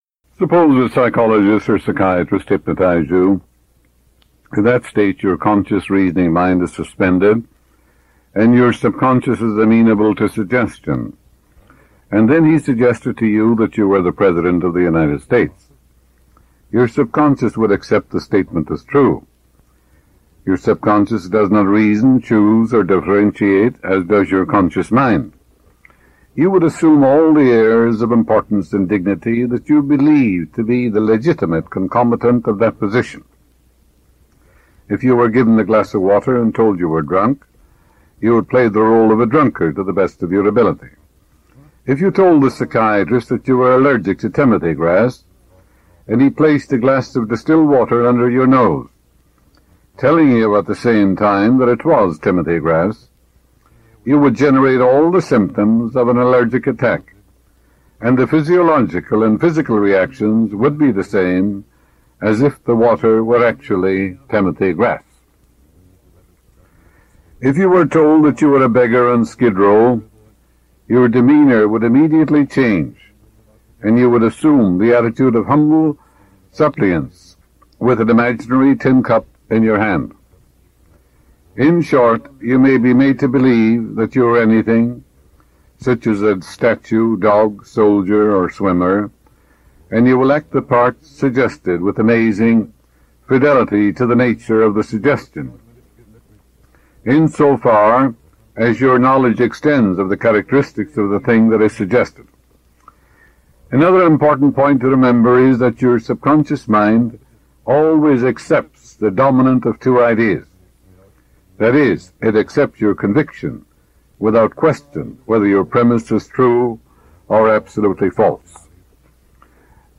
Joseph Murphy- The Power Of The Subconscious Mind (Rare Talk)